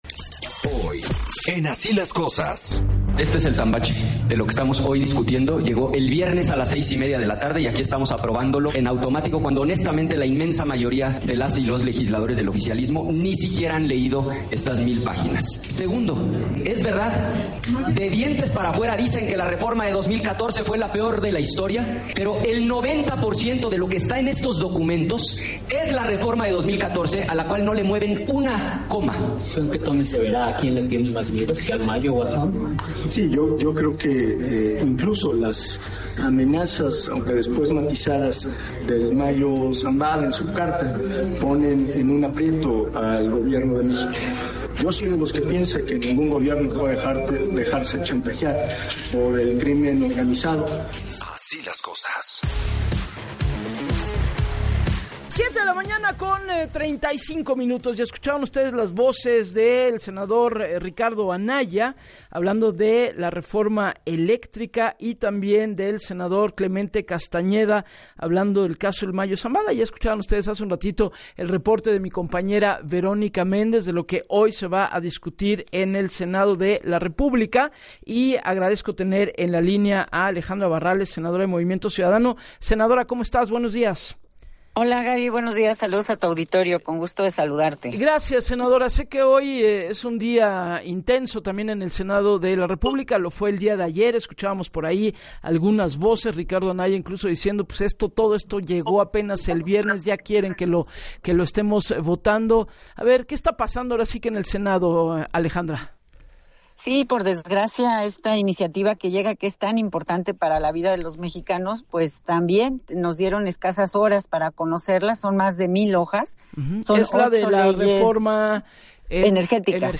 En entrevista para “Así las Cosas” con Gabriela Warkentin, la senadora de Movimiento Ciudadano reconoció que “lo que tiene que ver con justicia energética nos parece una parte noble de esta iniciativa”; sin embargo, en el Senado no se les permite debatir por lo que su respuesta debe ser “todo sí o todo no”, de ahí la preocupación de MC por la intención de crear “una supersecretaría que tendría superfacultades”.